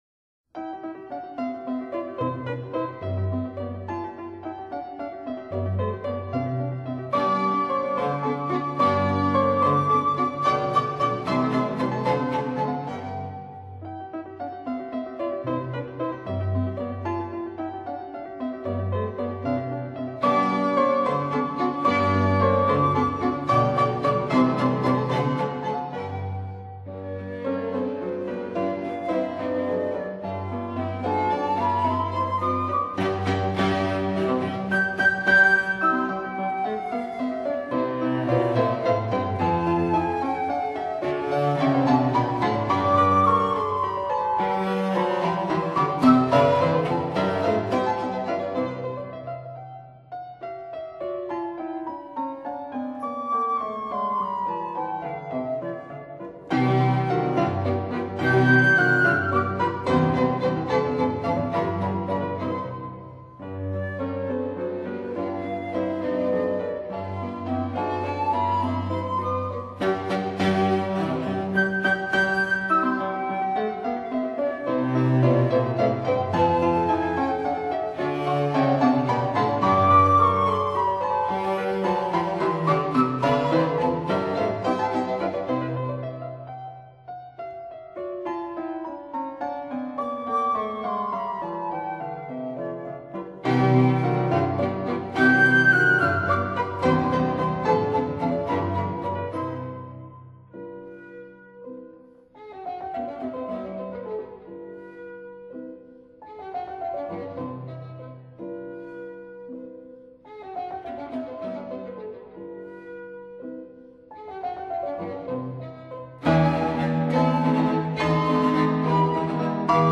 transcr.for flute quartet